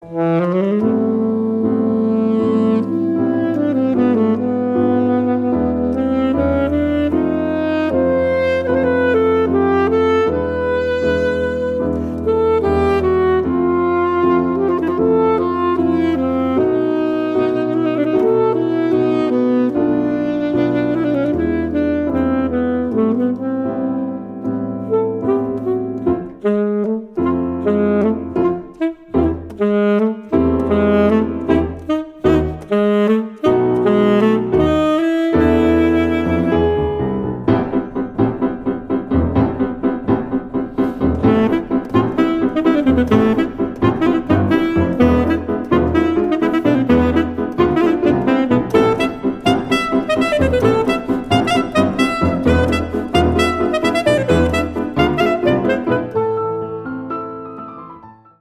hone alto et piano